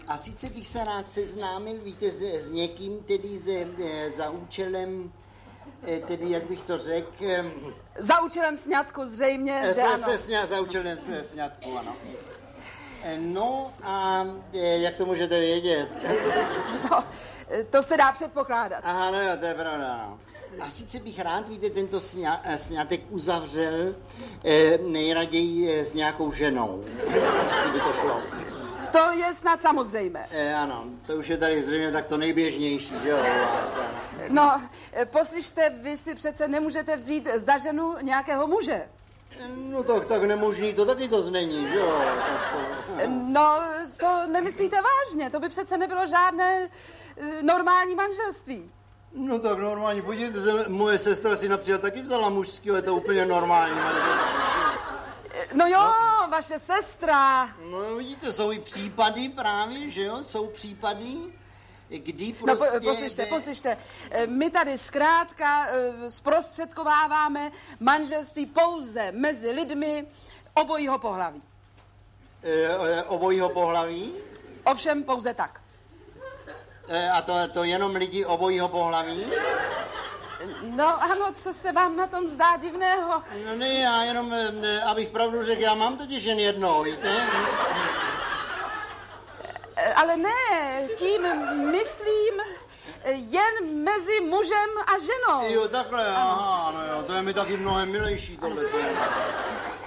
Audio kniha